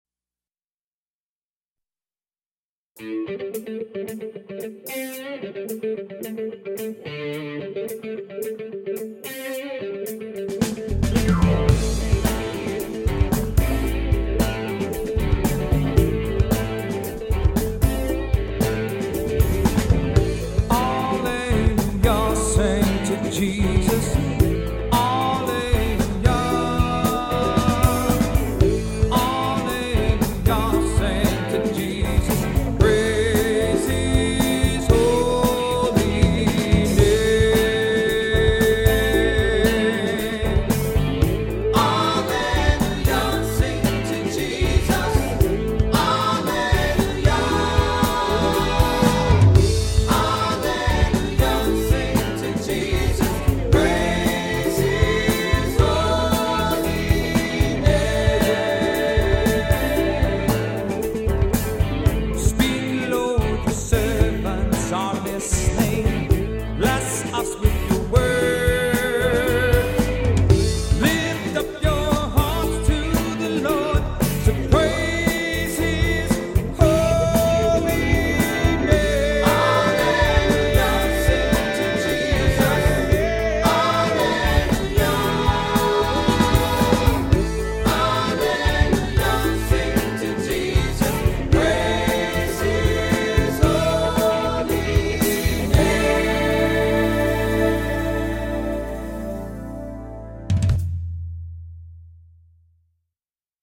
Voicing: Assembly, cantor,SATB